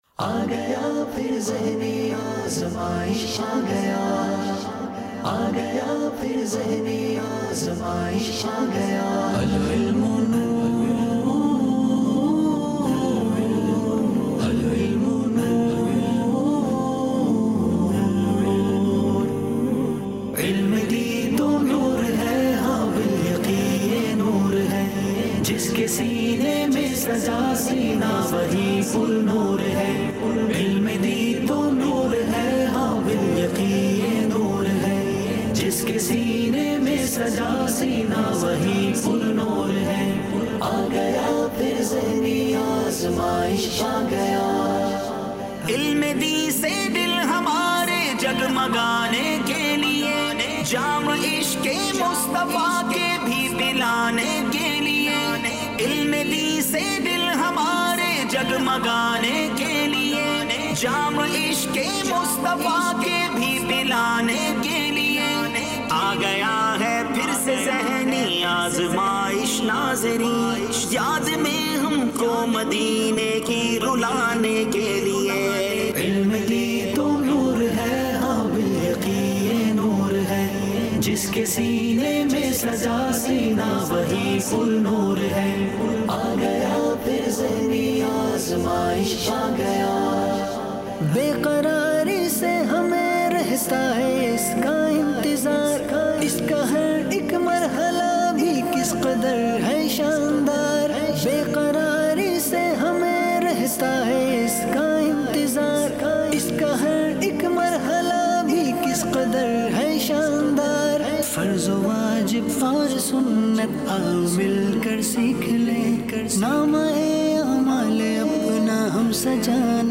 New Kalam 2025